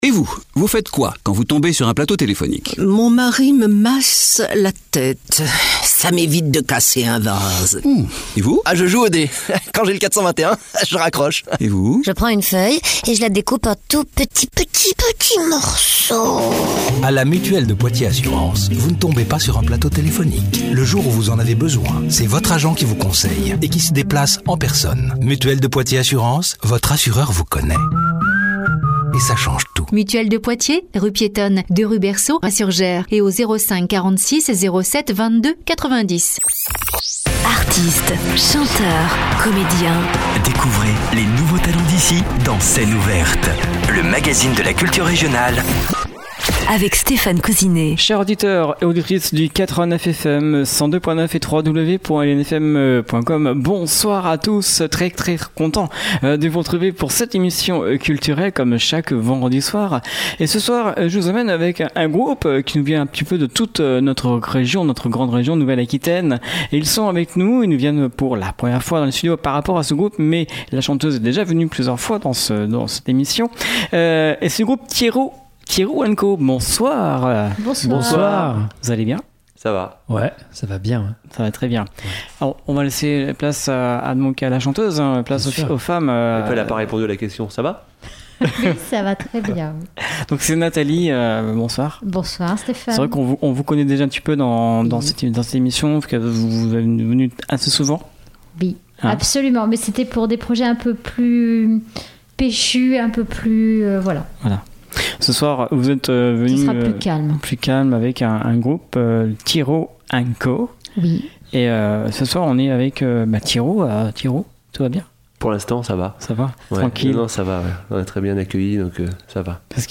trio
une ambiance intimiste et des souvenirs à partager.